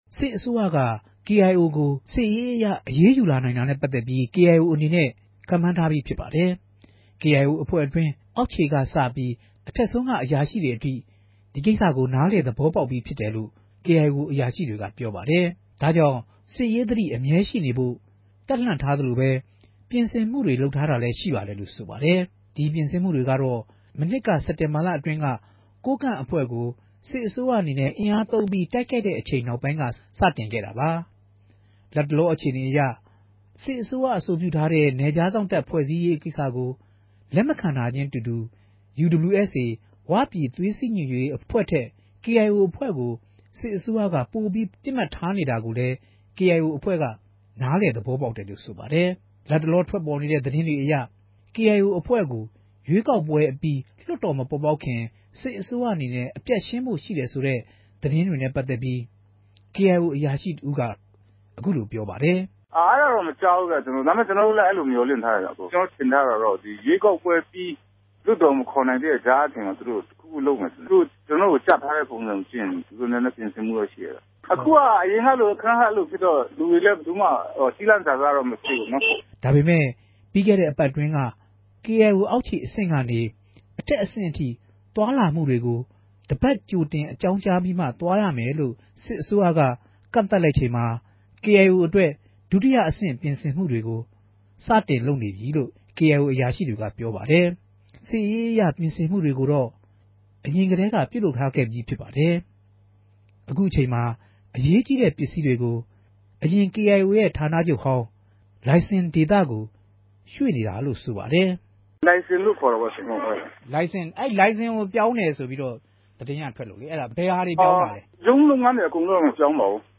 သတင်းတင်ပြချက်။